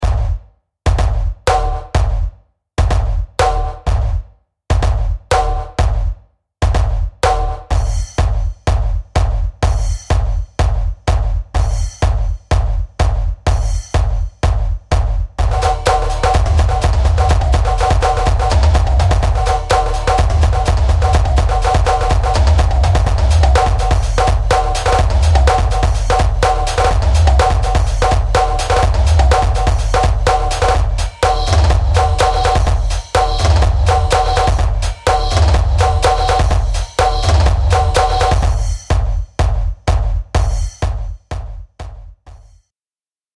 and attention commanding drum loop series
Over 200 loops of weaponry for